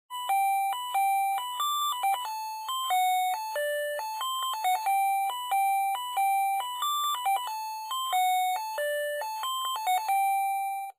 ZVONČEK 16 MELÓDIÍ
• elektronický
• zvuk: 16 striedajúcich sa druhov melódií